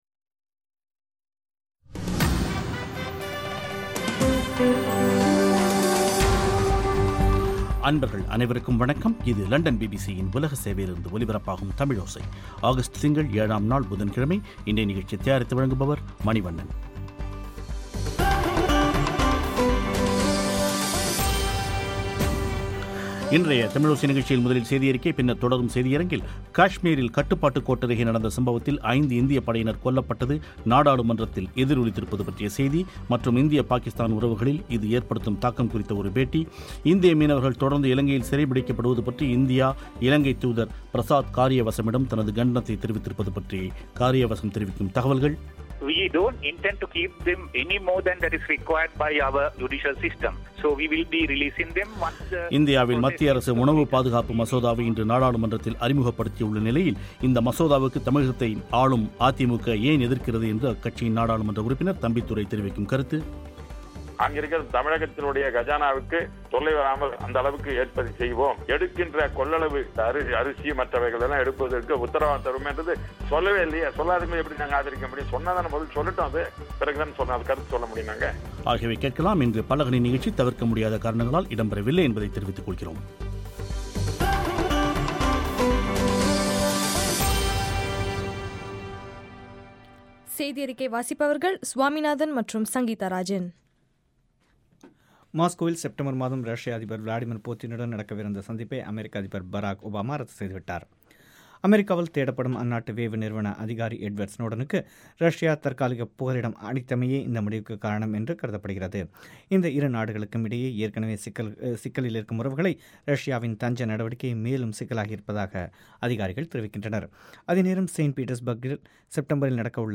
இன்றைய தமிழோசை நிகழ்ச்சியில் காஷ்மீரில் கட்டுப்பாட்டுக் கோட்டருகே நடந்த சம்பவத்தில் ஐந்து இந்தியப் படையினர் கொல்லப்பட்ட்து நாடாளுமன்றத்தில் எதிரொலித்திருப்பது பற்றிய செய்தி, மற்றும் இந்திய பாகிஸ்தான் உறவுகளில் இது ஏற்படுத்தும் தாக்கம் குறித்த ஒரு பேட்டி